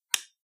switch4.ogg